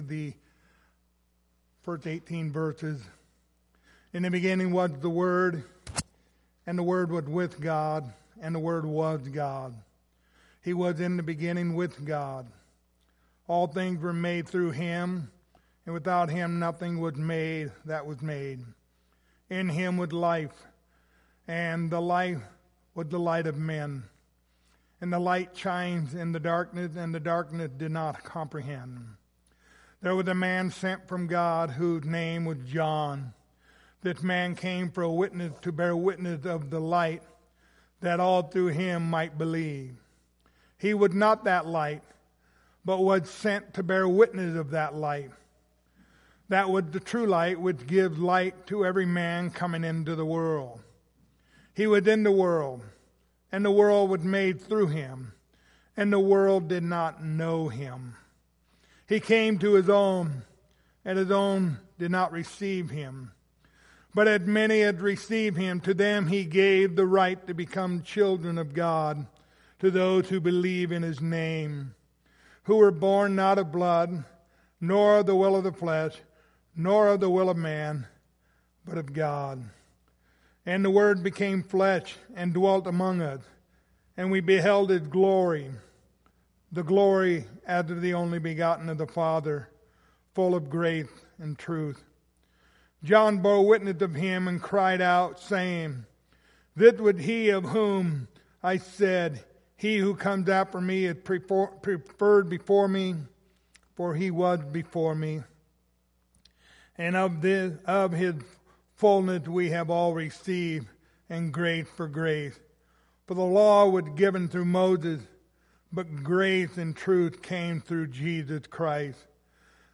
The Gospel of John Passage: John 1:9-13 Service Type: Wednesday Evening Topics